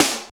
46.06 SNR.wav